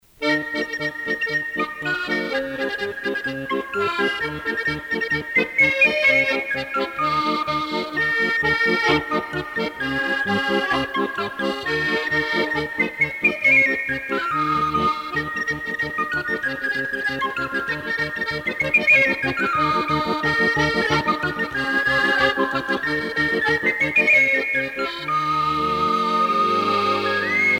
danse : hornpipe
Pièce musicale éditée